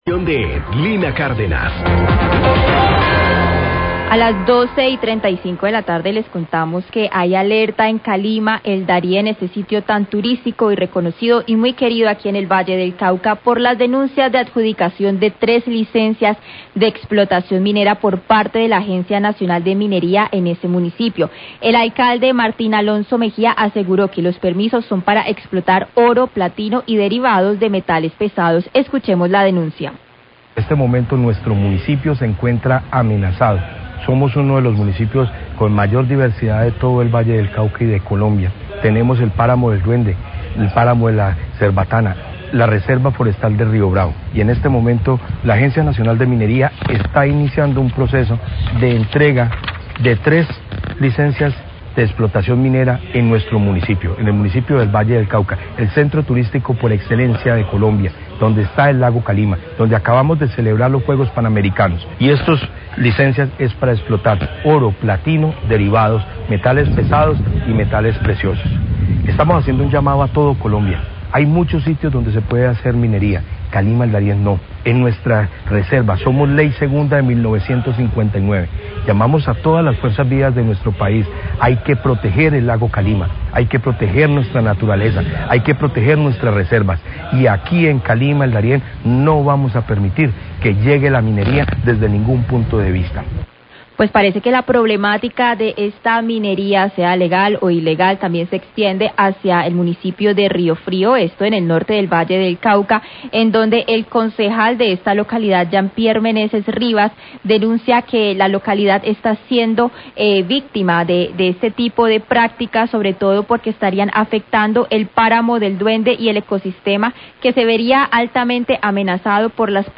Radio
El Alcalde de Cali-El Darién, Martín Alonso Mejía, denuncia la adjudicación de tres licencias mineras por parte de  la Agencia Nacional de Minería, exploraciones que afectarían los ecosistemas del Páramo del Duende y Lago Calima. El concejal de Ríofrio, Jean Pierre Meneses, también expresa su rechazo a estas prácticas mineras.